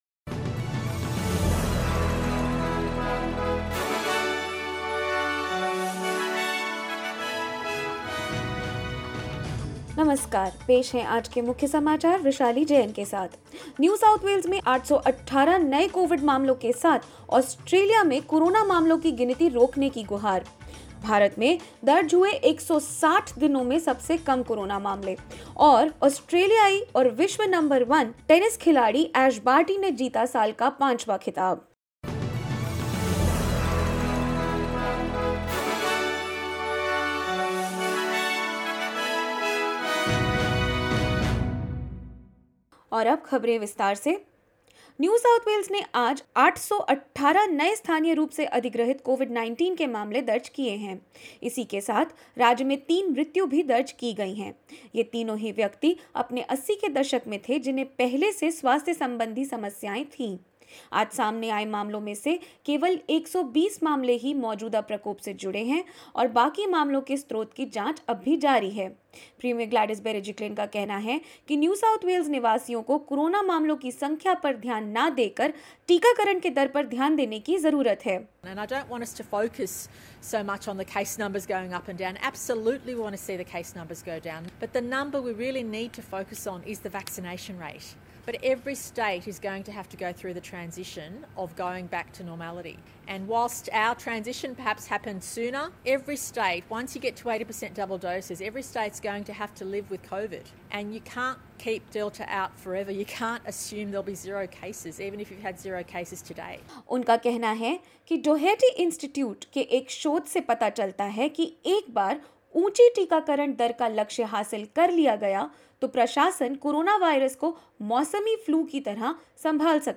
In this latest SBS Hindi News bulletin of Australia and India: NSW records 818 new locally transmitted cases, 3 deaths; Prime Minister Scott Morrison tells state governments lockdowns should end once vaccination targets are met and more.